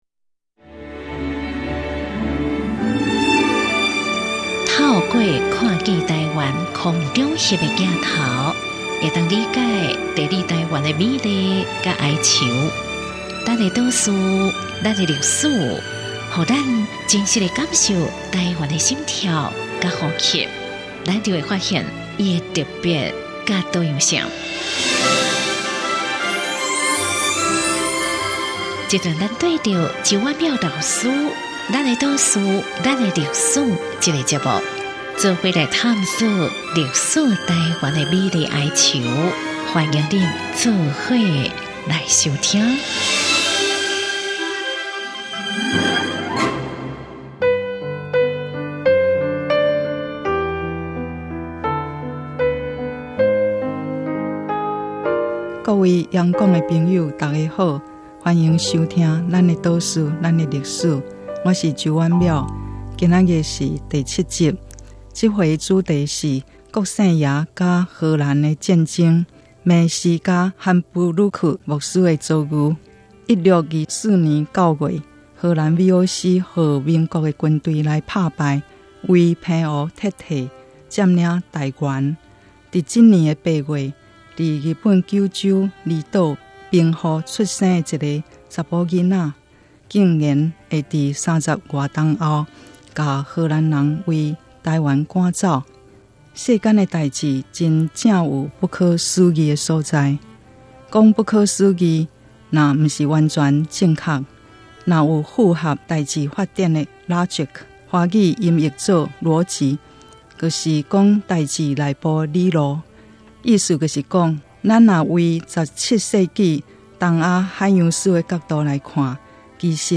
原文出處 節目名稱：Lán-ê 島嶼．lán-ê歷史 播出時間：2017年11月13日 主講者：周婉窈老師 Lán ê 島嶼．lán ê 歷史 透過「看見臺灣」空拍鏡頭，我們終於理解「地理臺灣」的美麗與哀愁。